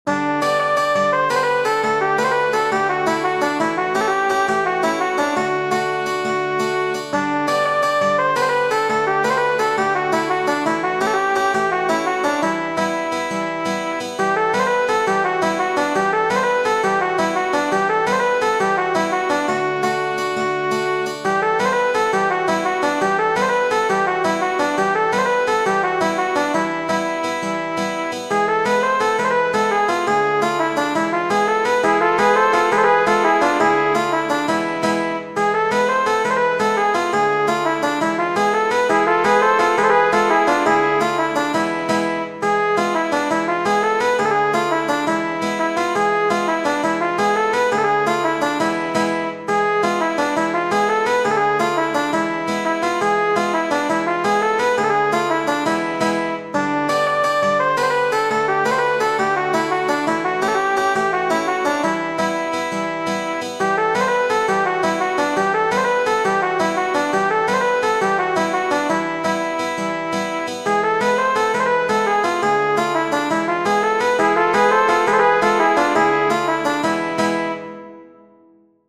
Tradizionale Genere: Folk "Pajduško horo" o "Pajduško oro" è un ballo tradizionale dei Balcani.